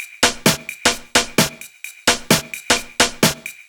Drum Loops 130bpm